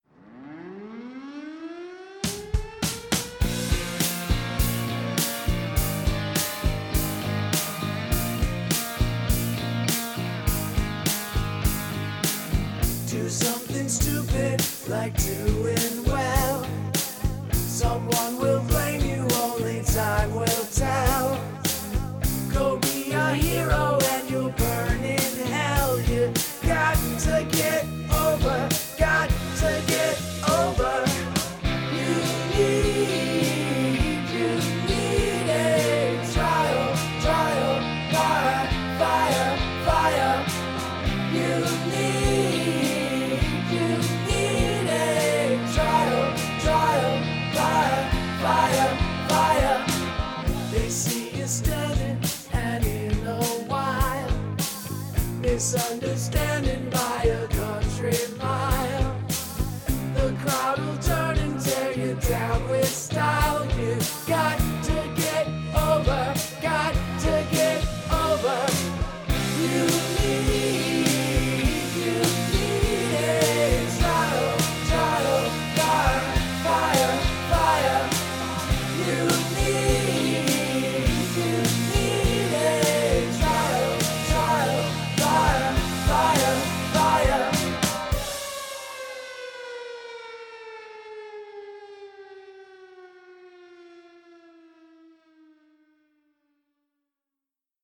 Incorporate sirens